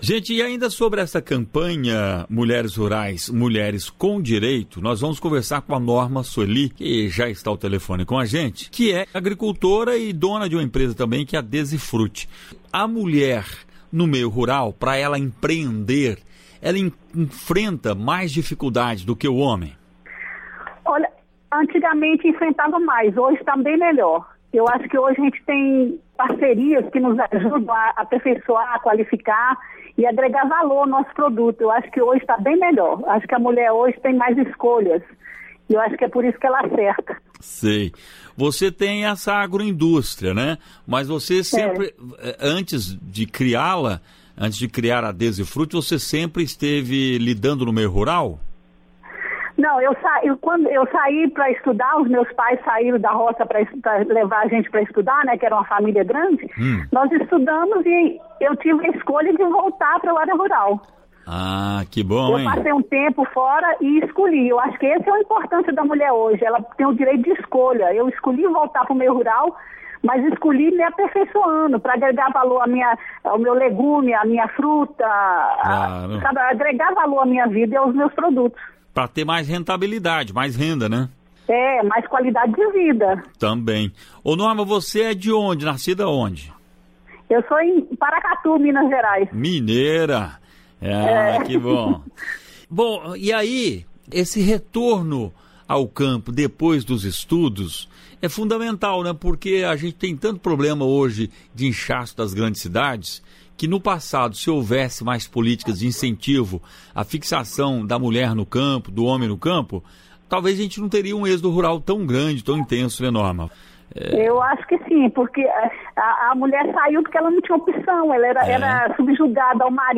Entrevista: Conheça o trabalho da empresária que escolheu voltar para o meio rural